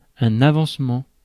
Ääntäminen
Synonyymit avance Ääntäminen France: IPA: /a.vɑ̃s.mɑ̃/ Haettu sana löytyi näillä lähdekielillä: ranska Käännöksiä ei löytynyt valitulle kohdekielelle.